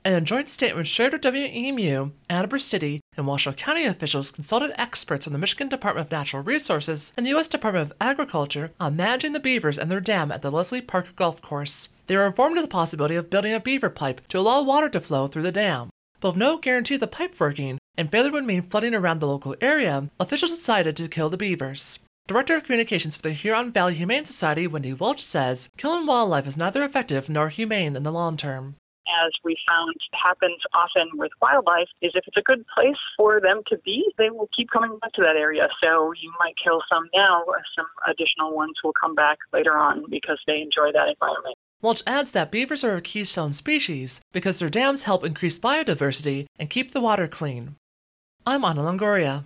Including this from a very unusual voice on public radio: